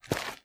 High Quality Footsteps
STEPS Dirt, Walk 10.wav